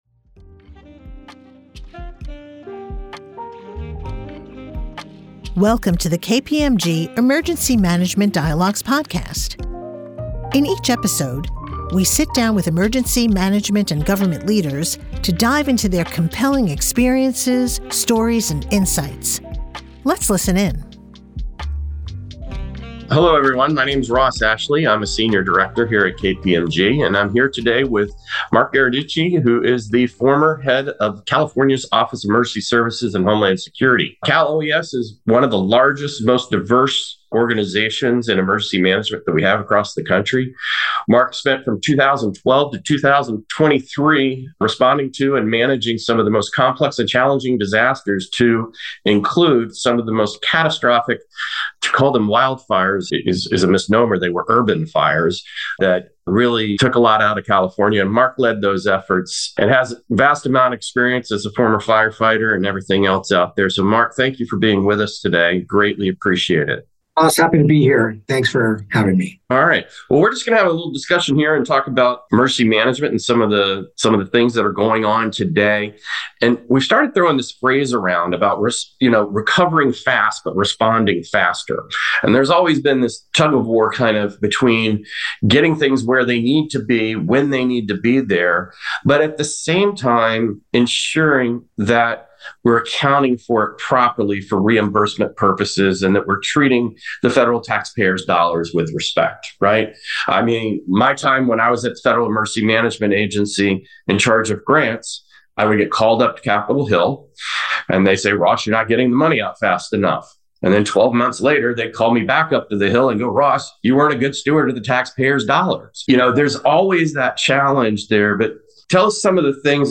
an insightful discussion on the evolving role of emergency management and California’s experiences embracing technology, innovation, and a proactive approach to emergency management.